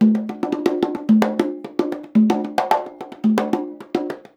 110 CONGA 2.wav